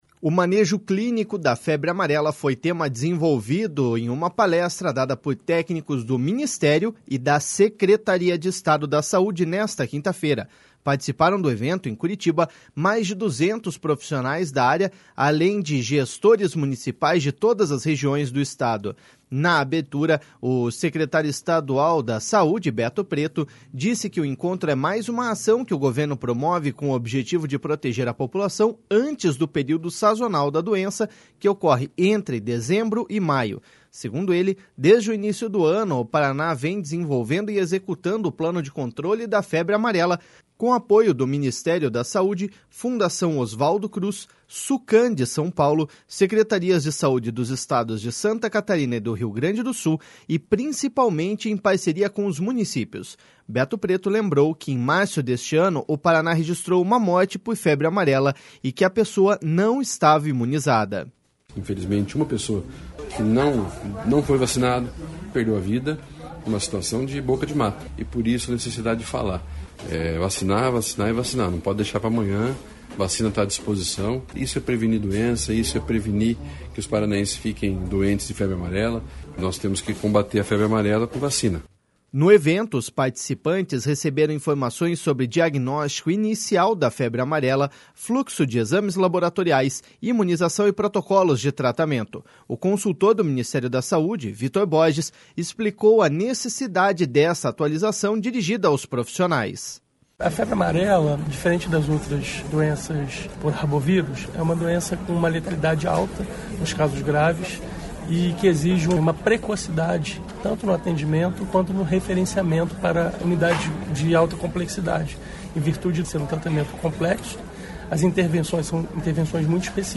Beto Preto lembrou que em março deste ano o Paraná registrou uma morte por febre amarela e que a pessoa não estava imunizada.// SONORA BETO PRETO.//